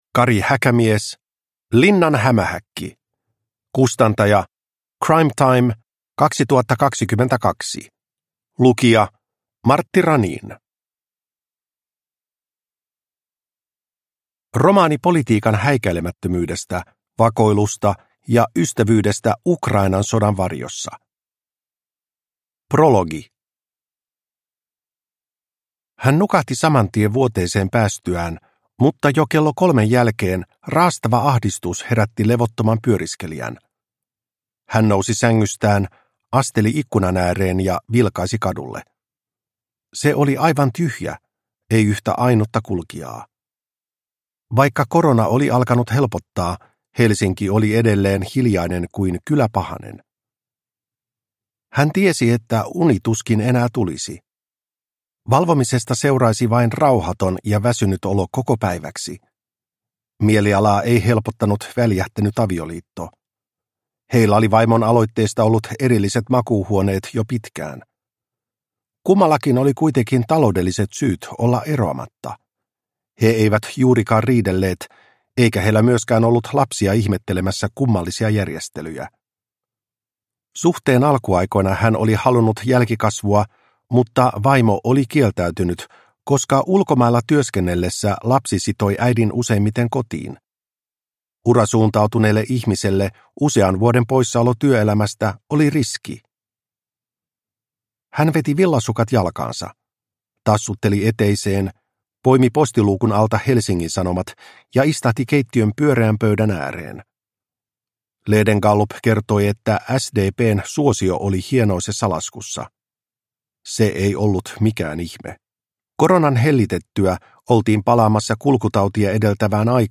Linnan hämähäkki – Ljudbok – Laddas ner